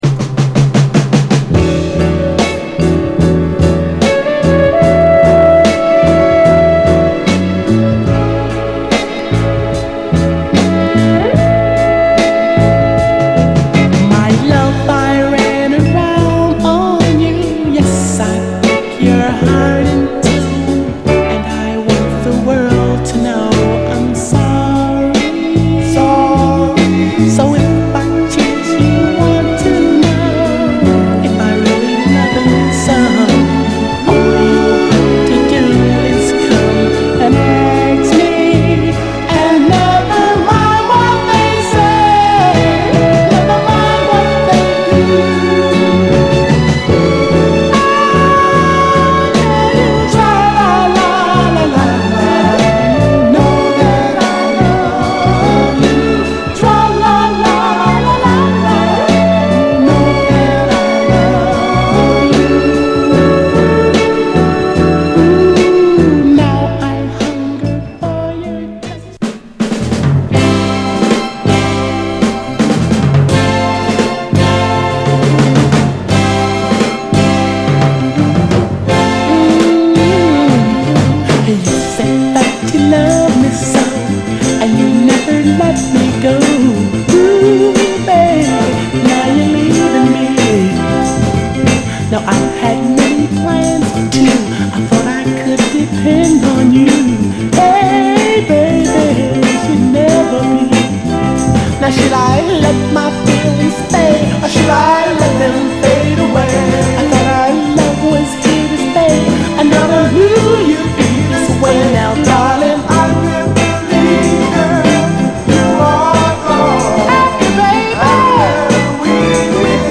両面ともに初期らしいストリングスが効いたスウィート・ソウルで素晴らしいです。
タフなARPヴァイナル・プレス。
※試聴音源は実際にお送りする商品から録音したものです※